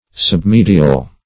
Submedial \Sub*me"di*al\, a. Lying under the middle.